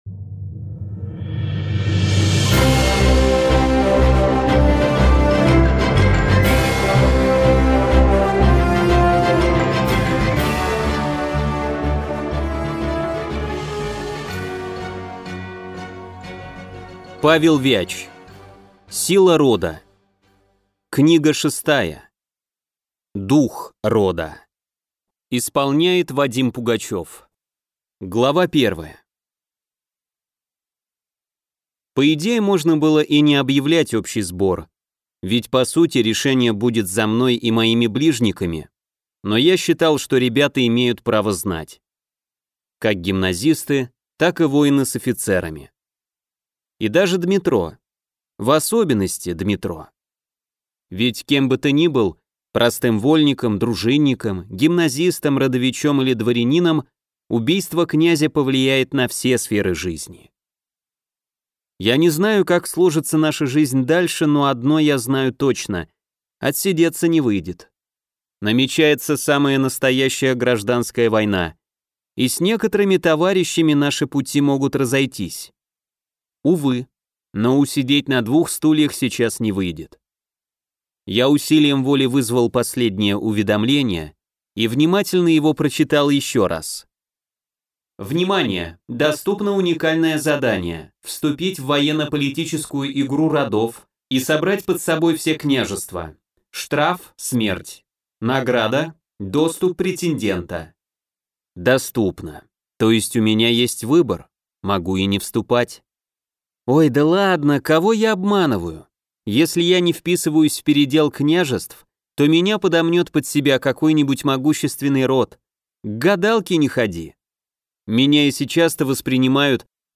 Аудиокнига Дух рода | Библиотека аудиокниг
Прослушать и бесплатно скачать фрагмент аудиокниги